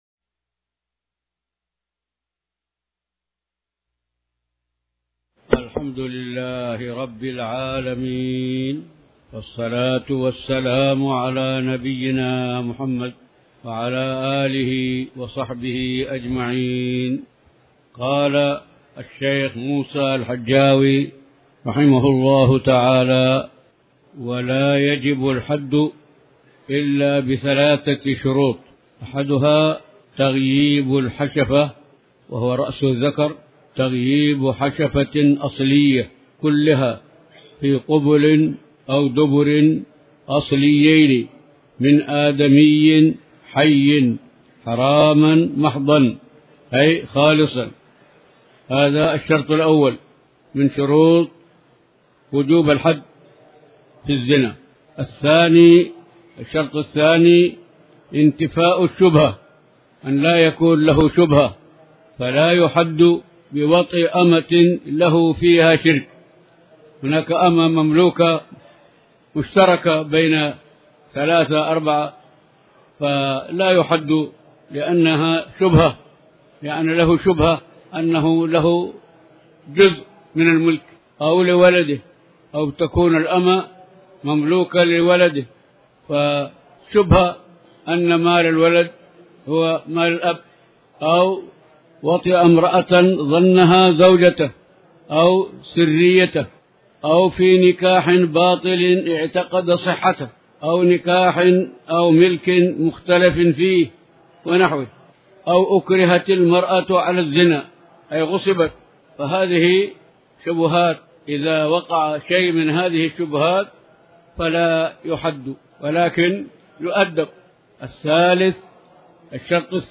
تاريخ النشر ١ محرم ١٤٤٠ هـ المكان: المسجد الحرام الشيخ